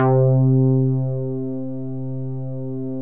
MOOG #2  C4.wav